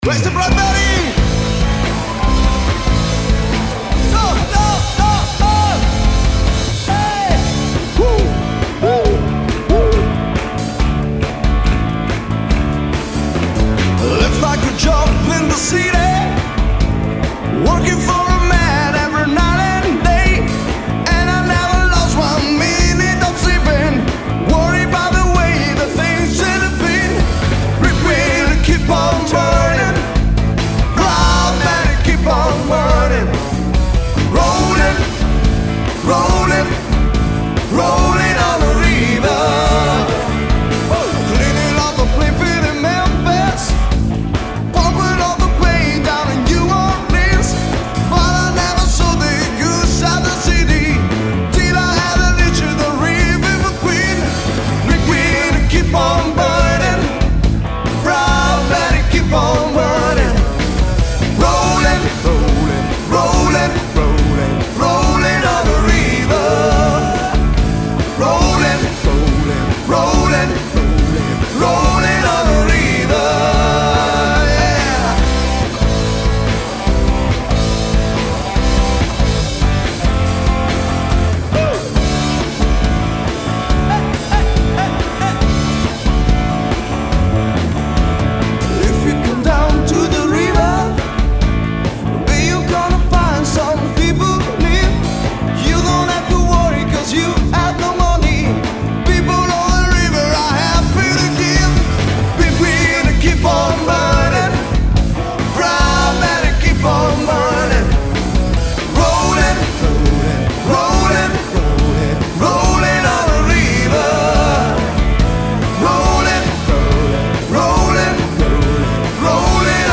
Voce, Chitarra Acustica
Voce, Basso
Chitarre
Batteria
Cover pop-rock